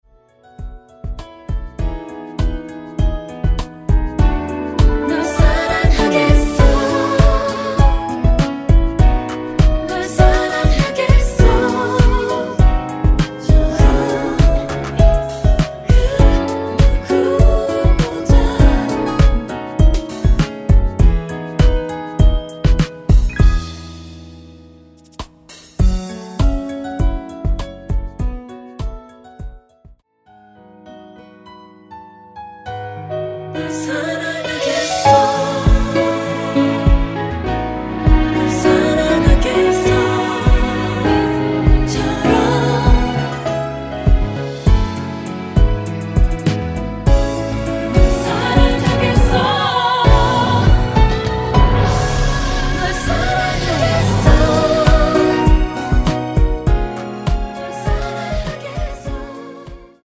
코러스 포함된 버젼 입니다.(미리듣기 참조 하세요)
◈ 곡명 옆 (-1)은 반음 내림, (+1)은 반음 올림 입니다.
앞부분30초, 뒷부분30초씩 편집해서 올려 드리고 있습니다.
중간에 음이 끈어지고 다시 나오는 이유는